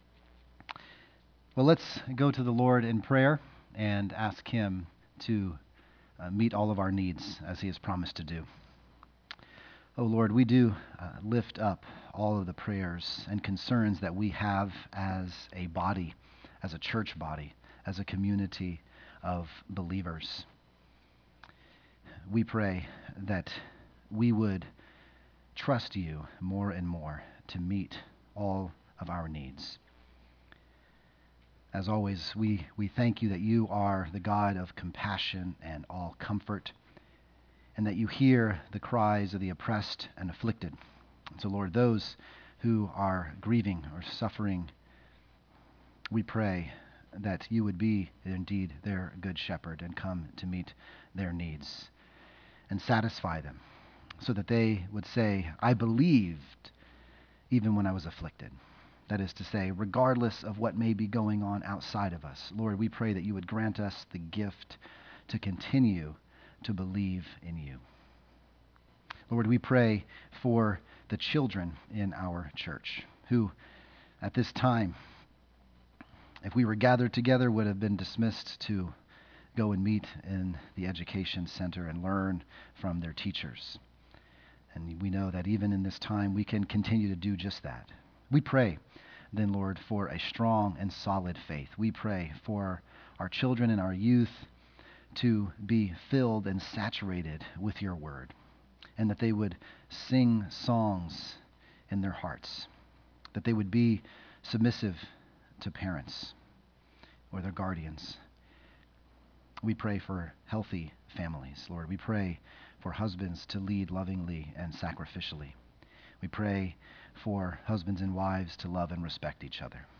( Sunday AM )